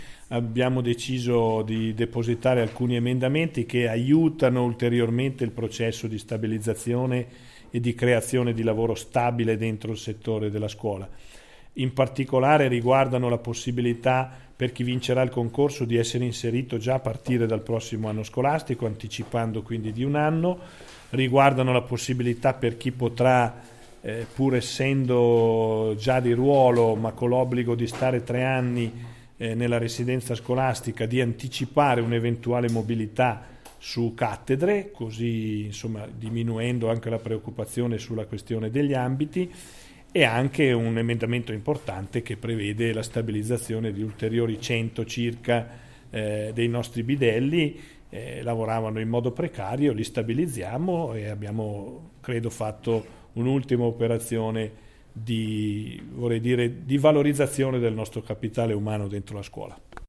A margine dell'incontro settimanale con la stampa, il governatore Ugo Rossi ne ha riassunto il contenuto.
In allegato l'intervista audio Fonte: Ufficio Stampa Istruzione e formazione Versione Stampabile Audio Rossi scuola Scarica il file (File audio/mpeg 940,00 kB)